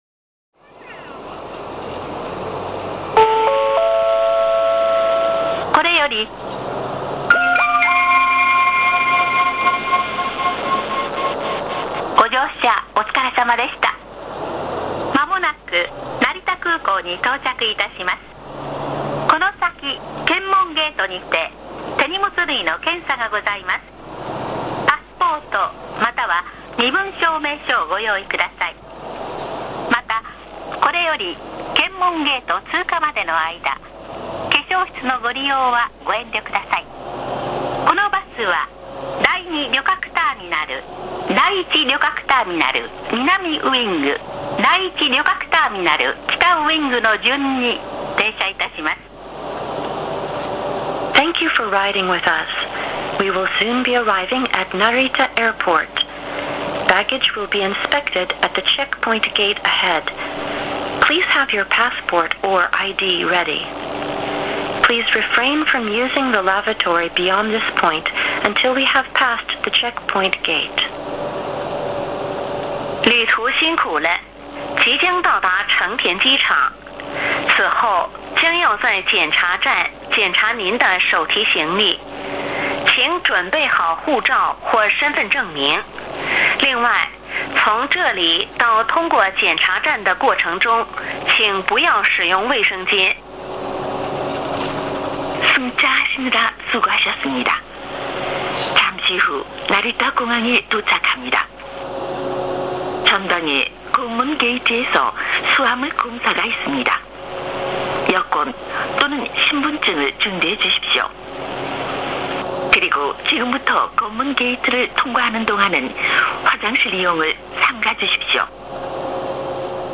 車内アナウンスコレクション
このページでは主に私がよく利用する東急バスと、メロディーチャイムが魅力の国際興業バスの車内放送を公開します。